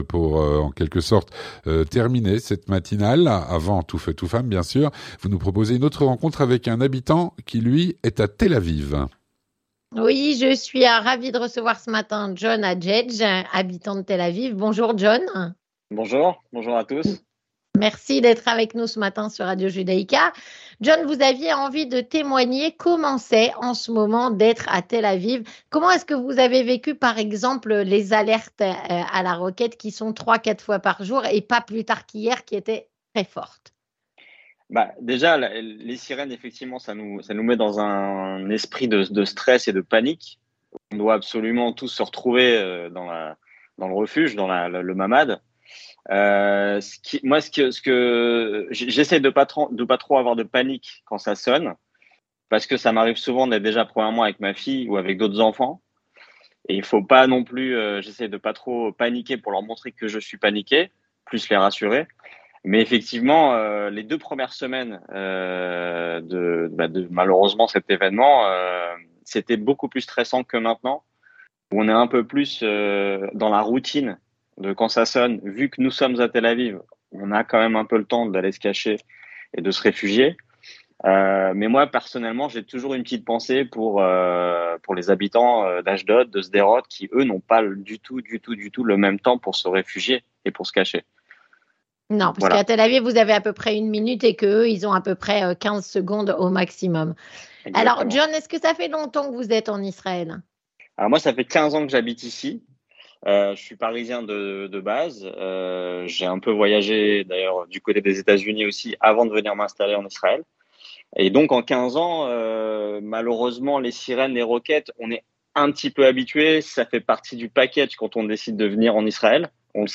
Comment vivent les habitants de Tel-Aviv en cette période de guerre : témoignage.